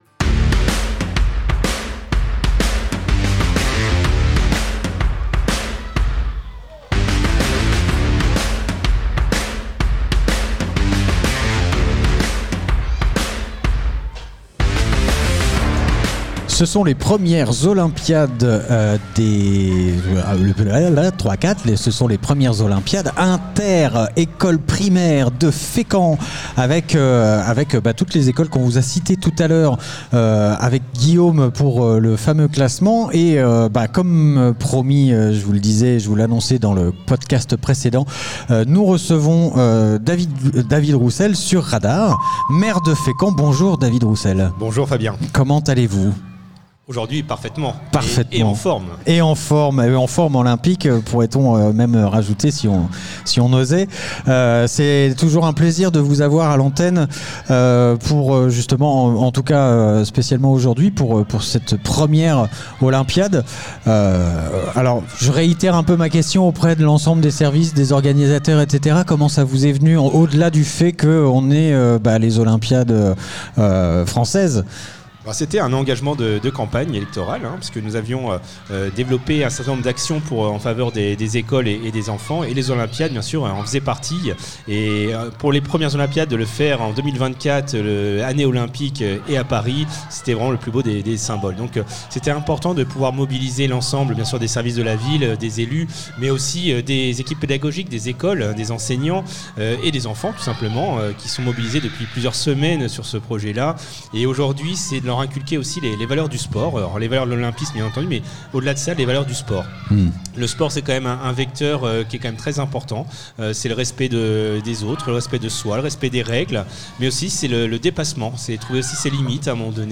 Olympiades des écoles Interview épreuves les olympiades des écoles